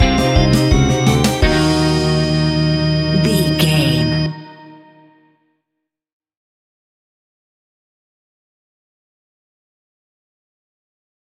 Atonal
tension
ominous
dark
eerie
creepy
horror music
Horror Pads
horror piano
Horror Synths